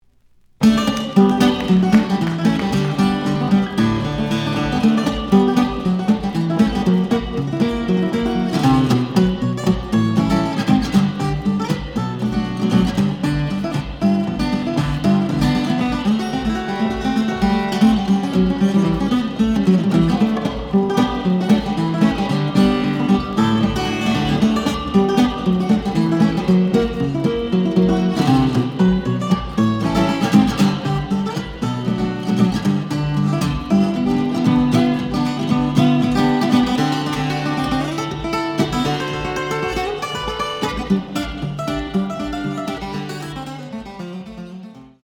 試聴は実際のレコードから録音しています。
●Format: 7 inch
●Genre: Rock / Pop